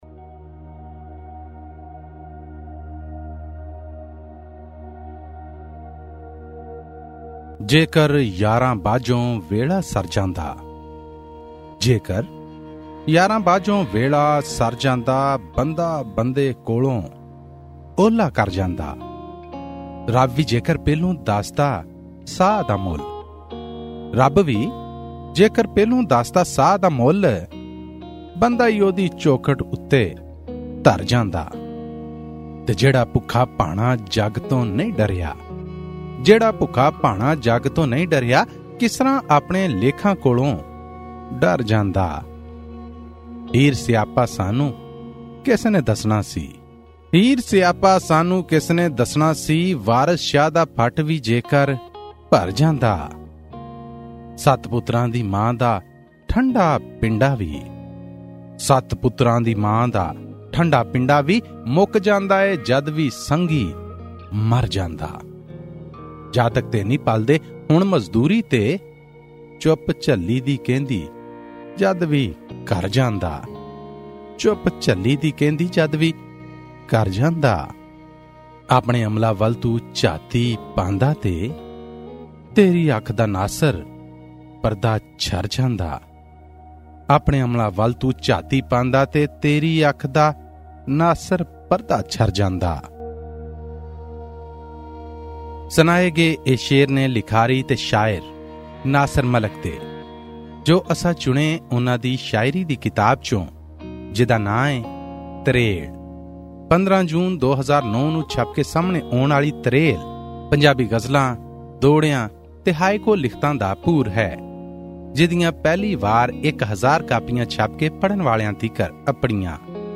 Book review: 'Tarel' by Pakistani Punjabi poet Nasir Malik